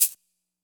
[Perc] Shaker 2.wav